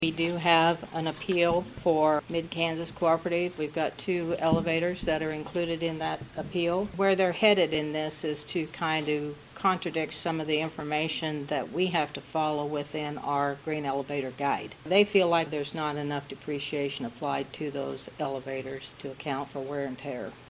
County Appraiser Lois Schlegel also presented updates from the Board of Tax Appeals during Monday’s meeting.